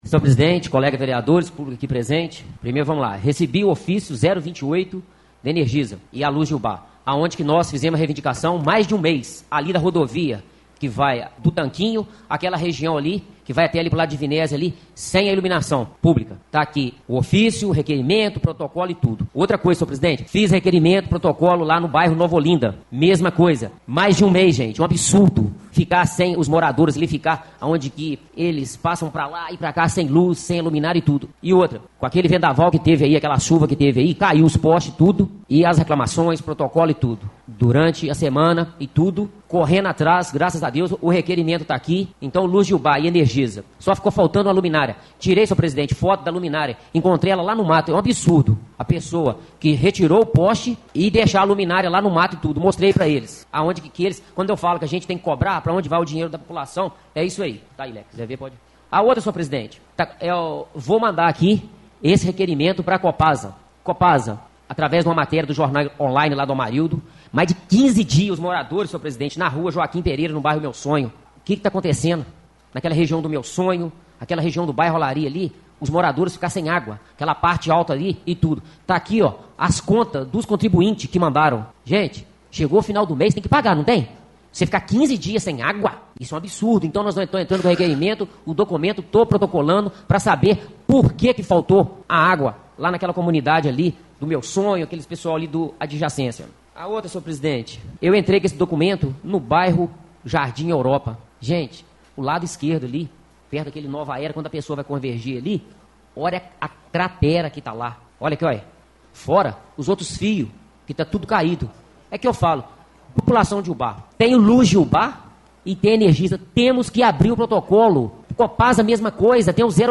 Na reunião ordinária dessa segunda-feira,27/03, no plenário da Câmara Municipal de Ubá, o vereador Gilson “Pica-Pau”, informou que fez requerimentos sobre o sistema de iluminação de abastecimento de água.